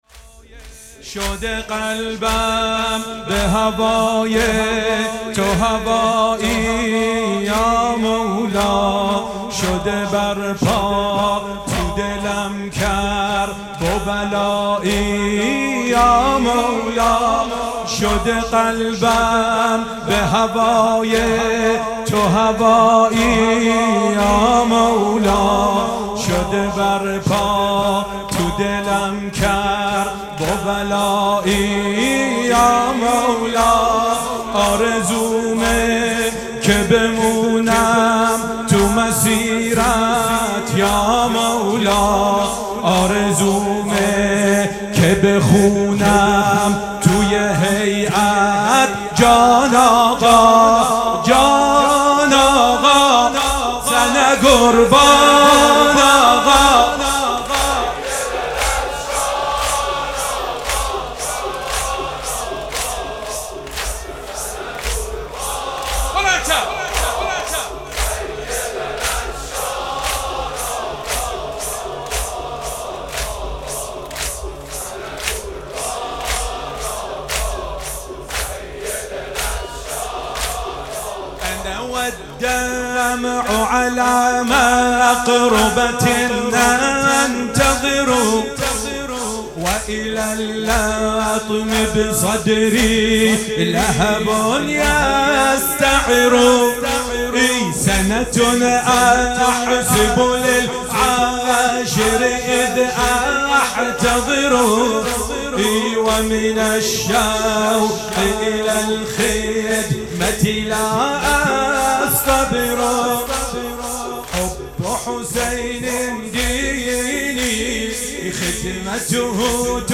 مداحی به سبک تک اجرا شده است.